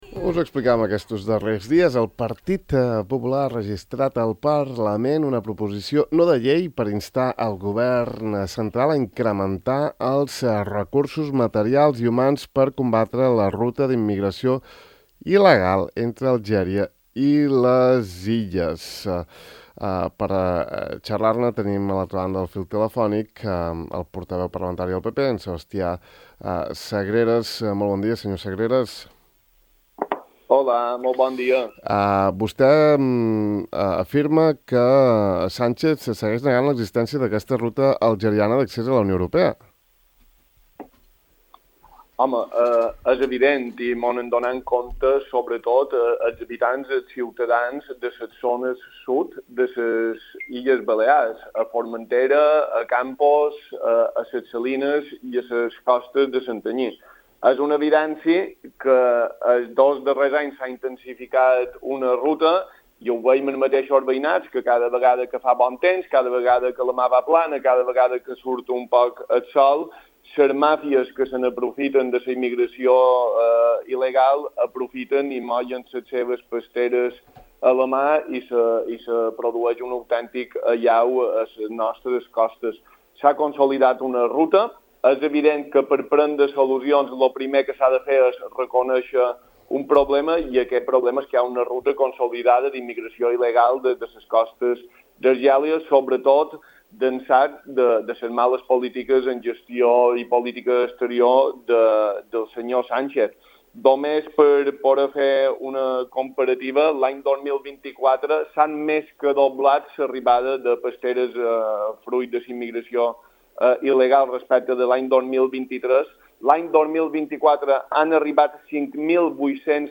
D’altra banda, Ràdio Illa ha preguntat a Sagreras sobre les afirmacions de l’expresident Córdoba del passat desembre, quan assegurà que hi havia un pacte segons el qual els consellers del PP en el si de Sa Unió no podien donar suport a una moció de censura contra ell.